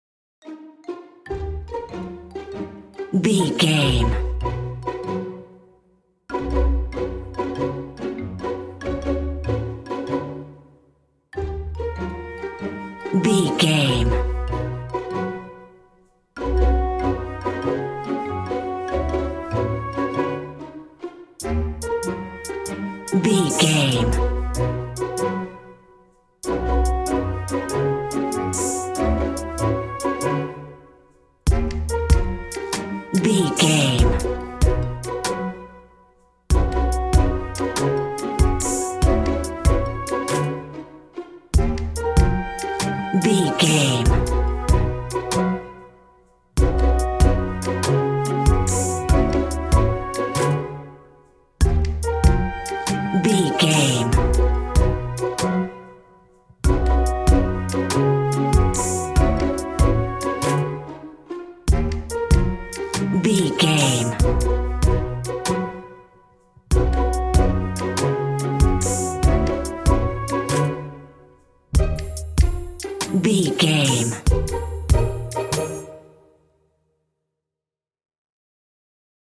Ionian/Major
orchestra
strings
percussion
flute
silly
circus
goofy
comical
cheerful
perky
Light hearted
quirky